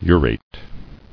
[u·rate]